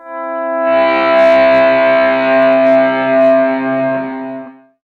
gtdTTE67015guitar-A.wav